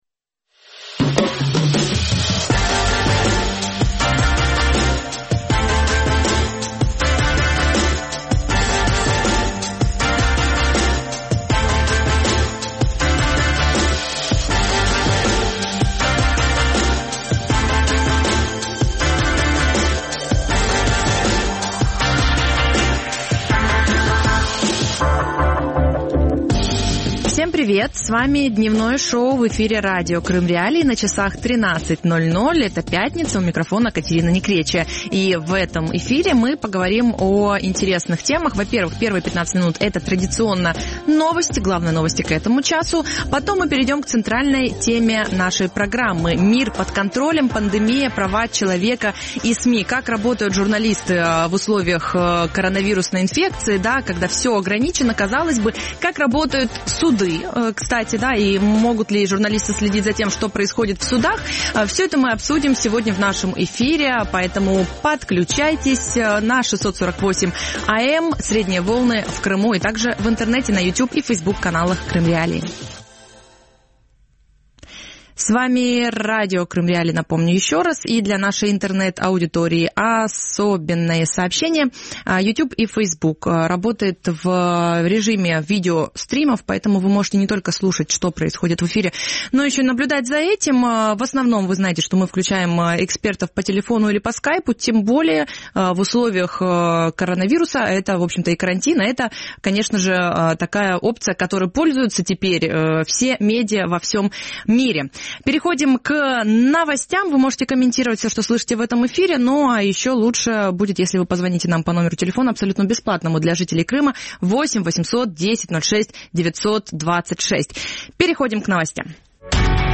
Мир под контролем. Пандемия, права человека и СМИ | Дневное ток-шоу